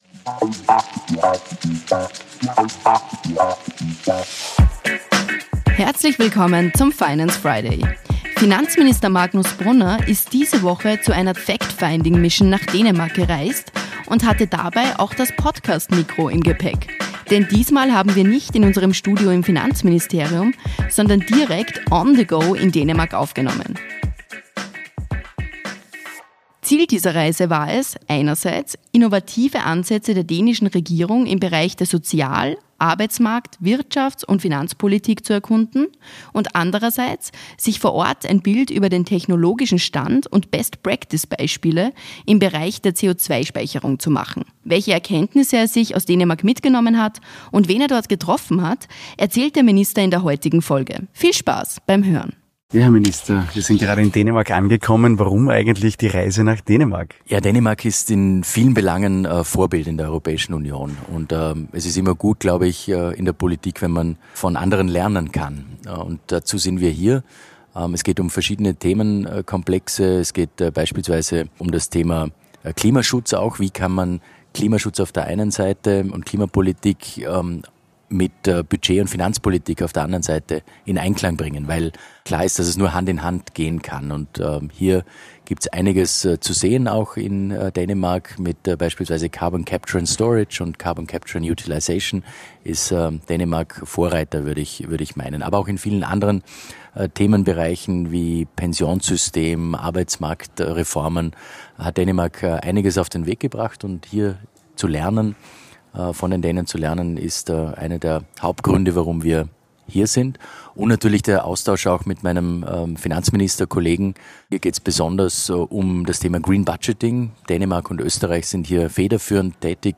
Studio im Finanzministerium, sondern direkt „on the go“ in Dänemark